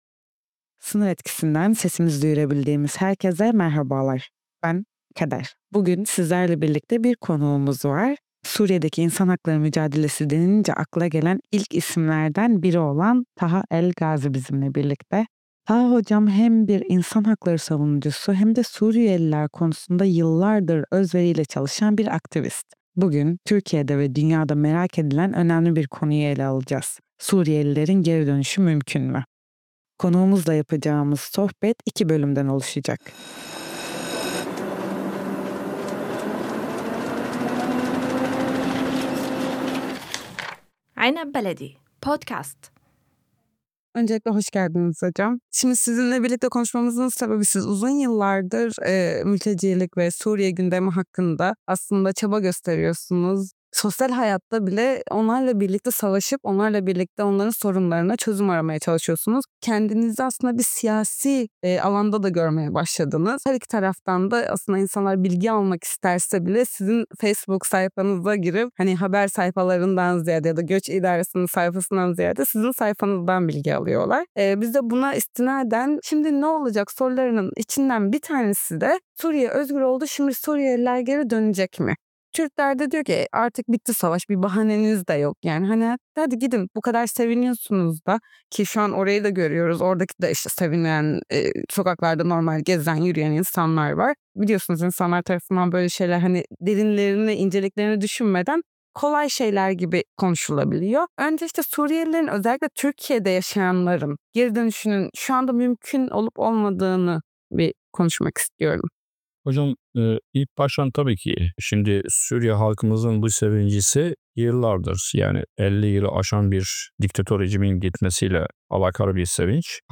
sohbet